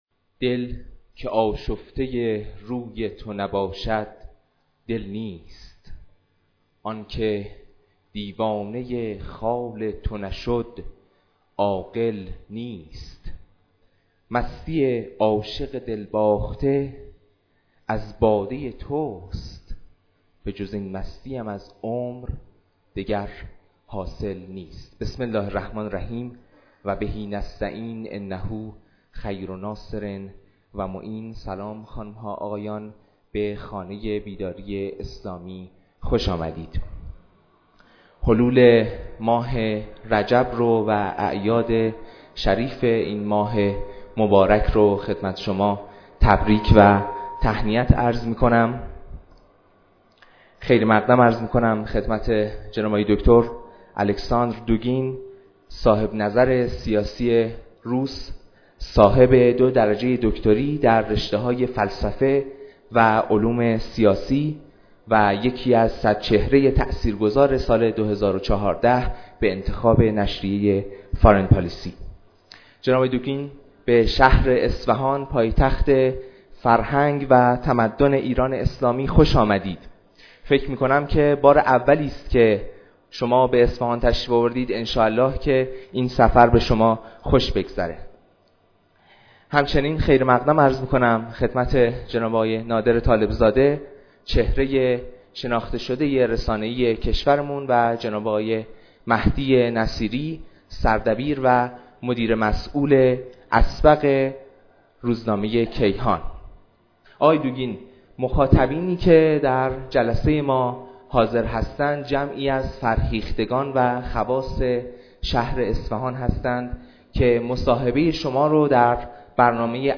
سخنرانی پرفوسورالکساندردوگین فیلسوف سیاسی روس ومشاور ولادیمیرپوتین درخصوص نظریه پردازی تئوری چهارم سیاست وباحضورنادرطالب زاده فعال رسانه درخانه بیداری اسلامی اصفهان